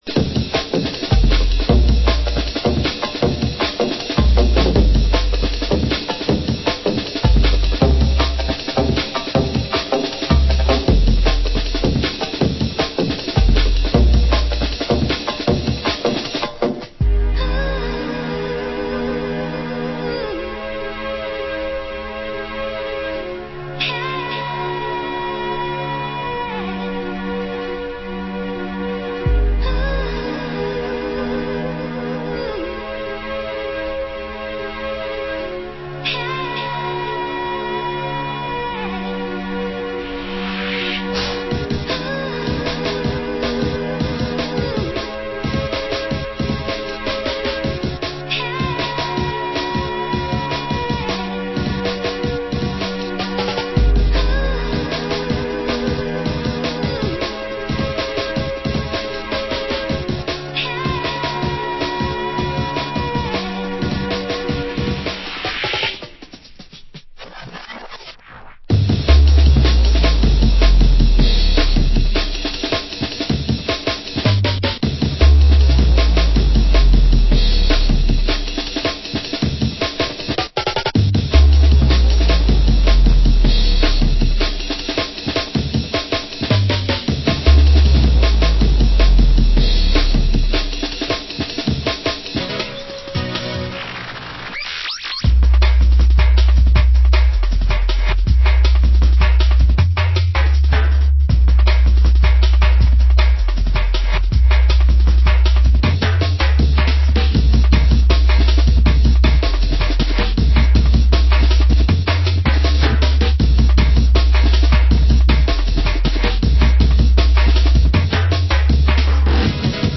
Format Vinyl 12 Inch
Genre Hardcore